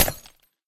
glass3.ogg